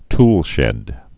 (tlshĕd)